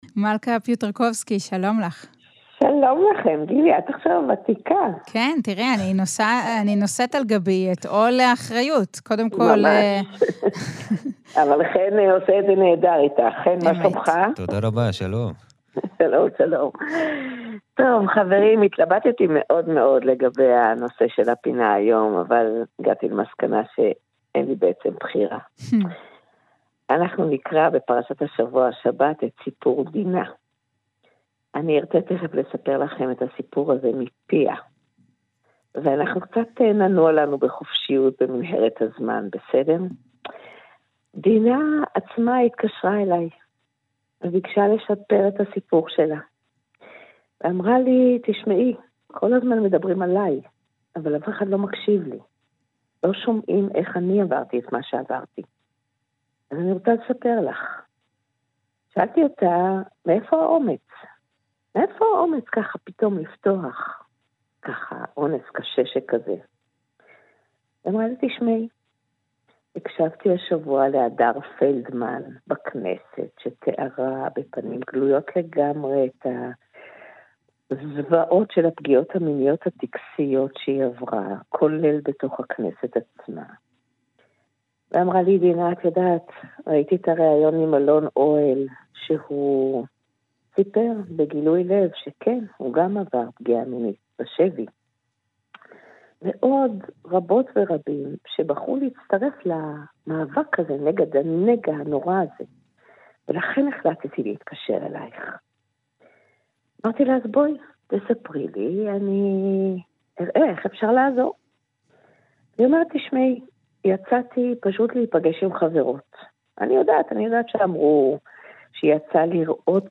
ראיון לכבוד שבוע דינה ברשת ב׳